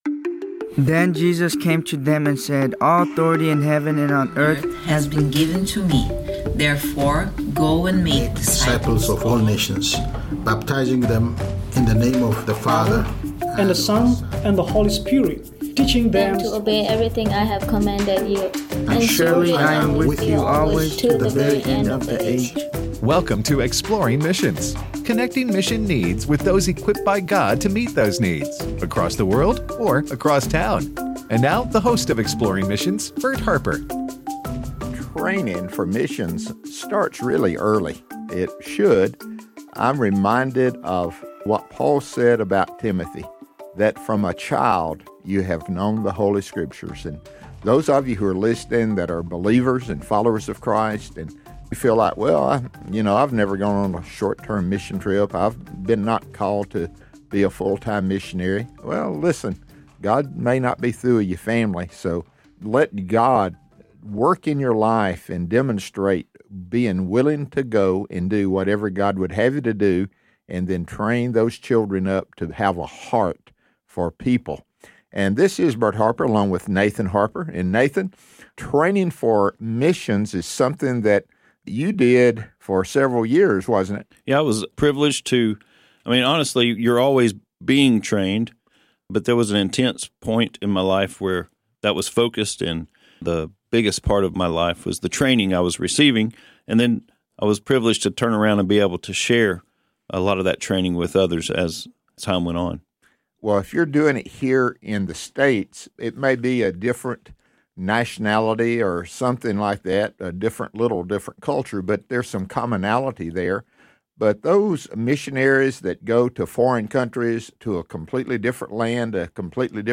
Teaching the Gospel: A Conversation